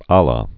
lä, ä lə, ălə)